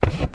High Quality Footsteps
Wood, Dense
MISC Wood, Foot Scrape 07.wav